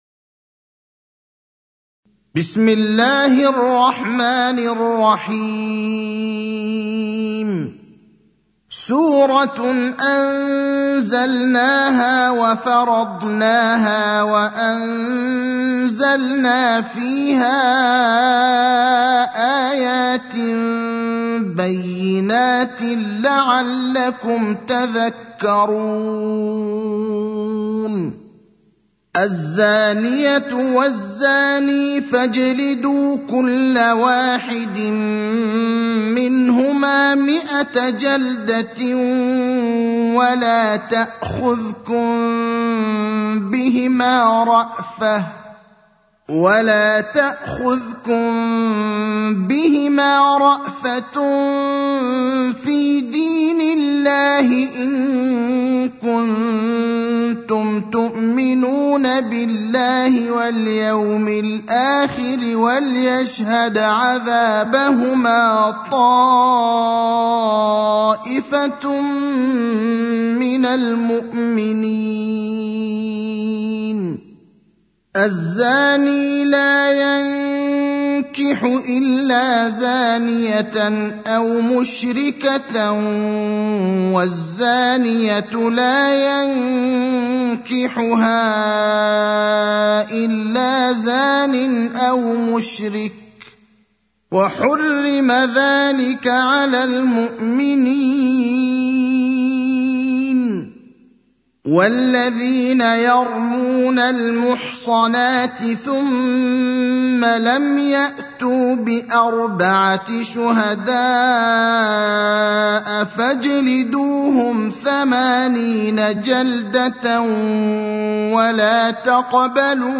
المكان: المسجد النبوي الشيخ: فضيلة الشيخ إبراهيم الأخضر فضيلة الشيخ إبراهيم الأخضر سورة النور